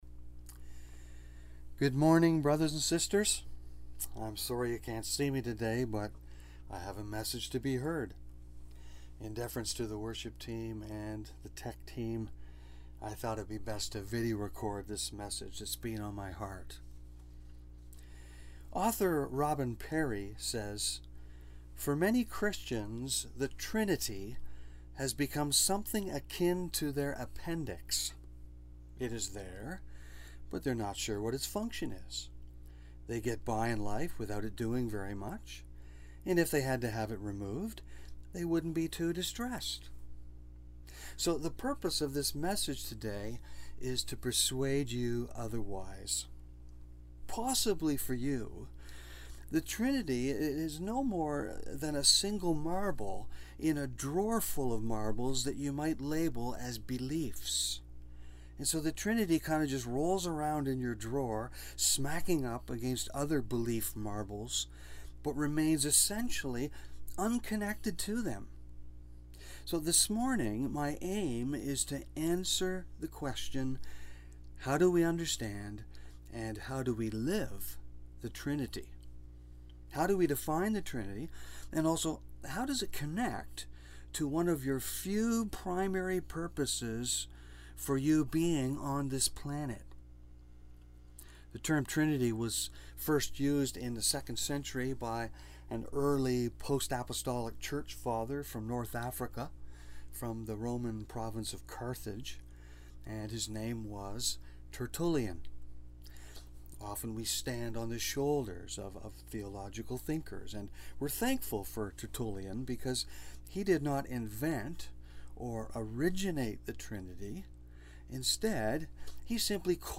BACK TO SERMON LIST Preacher